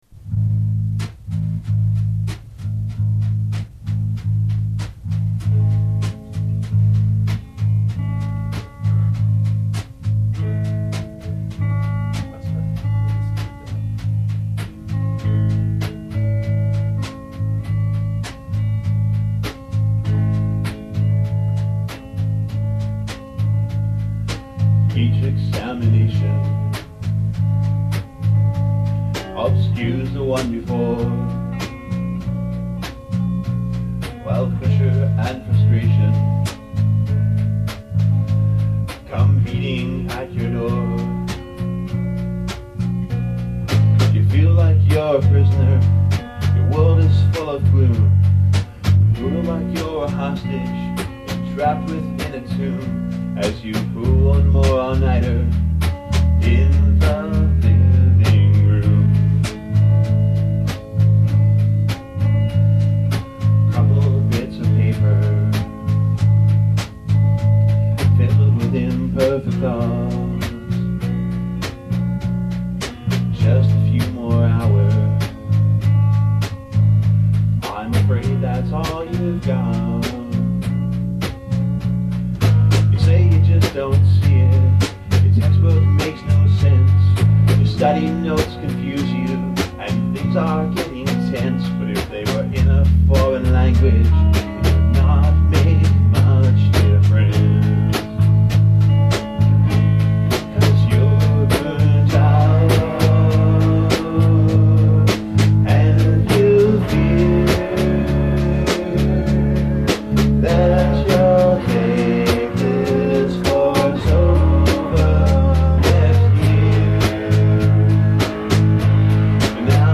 We’d play parodies, “Weird Al”-style
I’m on bass
drums
guitars